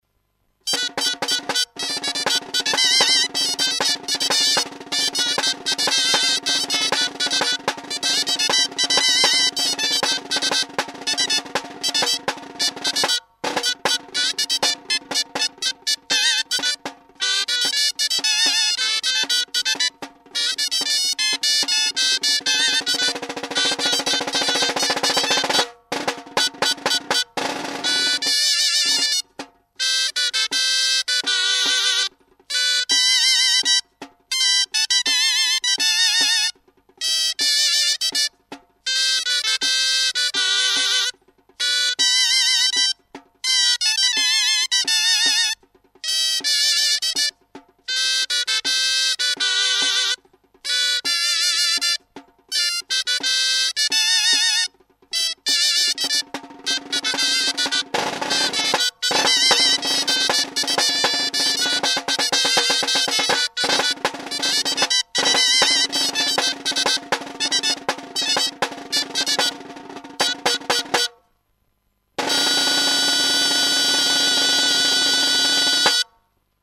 Membranófonos -> Golpeados -> Tambores con palos
JOTA. Lizarrako Gaiteroak.
Dultzaina-gaita taldearen ohizko danborra.